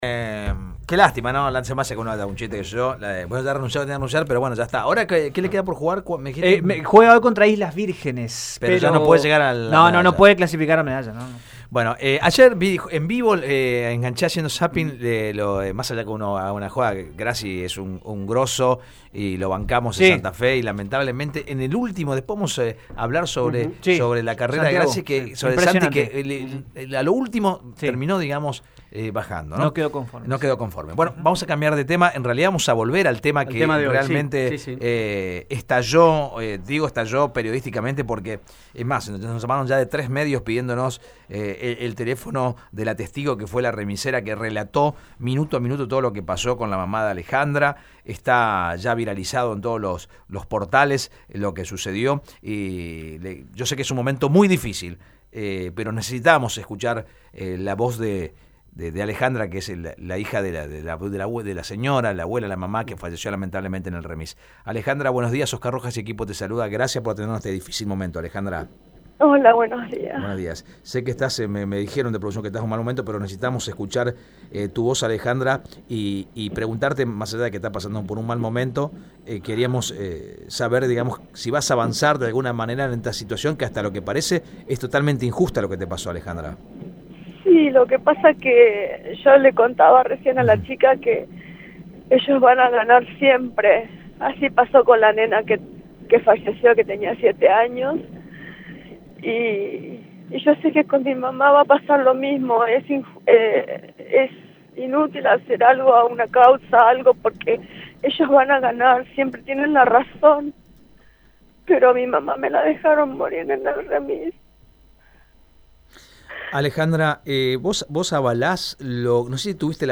Con lágrimas en los ojos y la voz quebrada